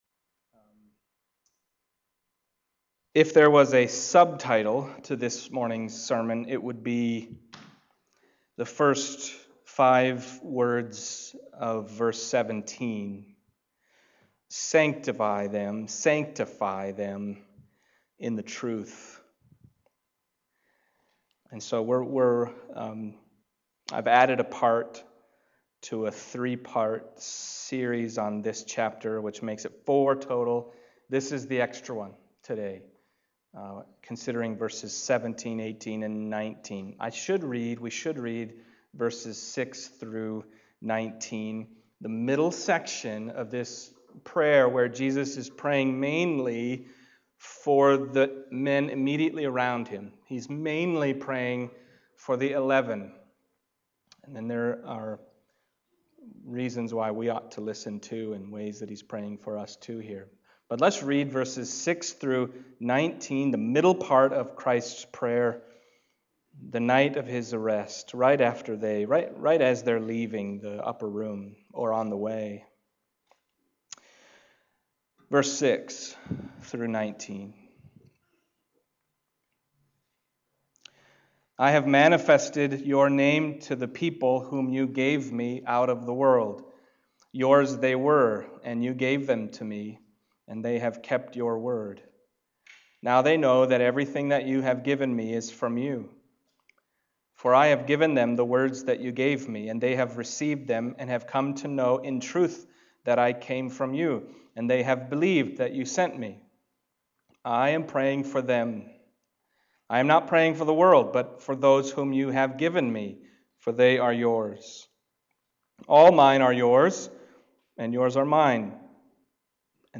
John Passage: John 17:17-19 Service Type: Sunday Morning John 17:17-19 « And Then God Prayed to God…